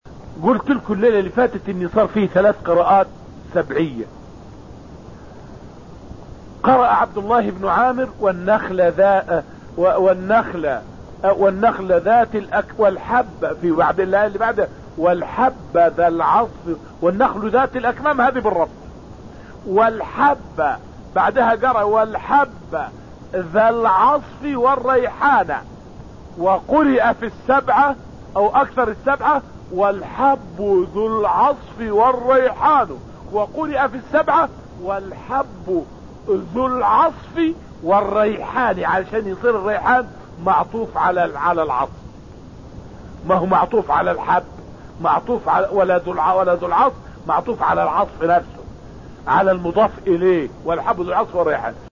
فائدة من الدرس الخامس من دروس تفسير سورة الرحمن والتي ألقيت في المسجد النبوي الشريف حول "والحب ذو العصف والريحان" بالقراءات السبعية.